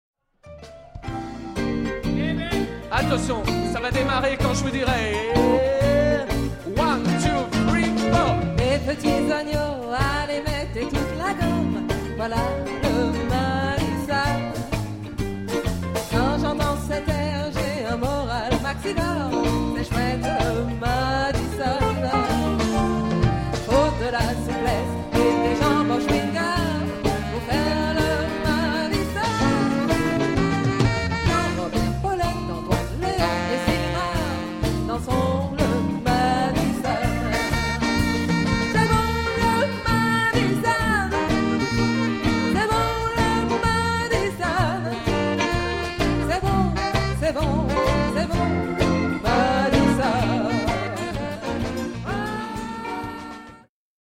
(madison)